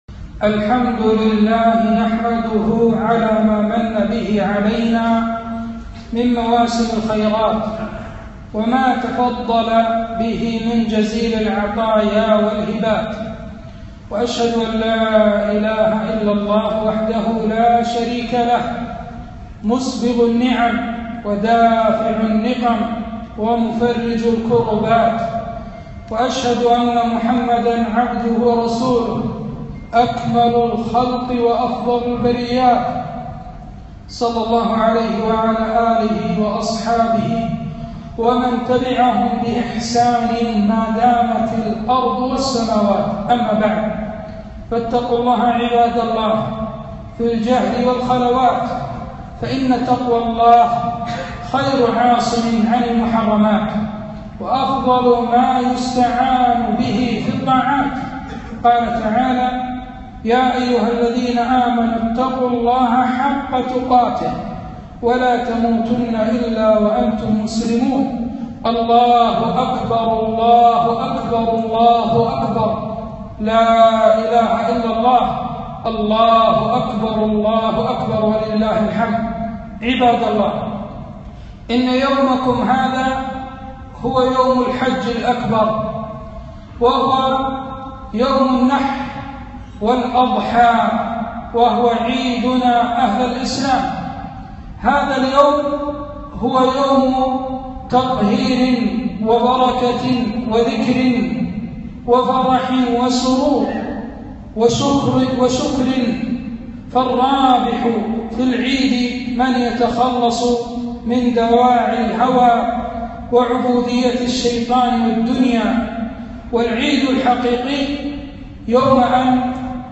فصل لربك وانحر- خطبة عيد الأضحى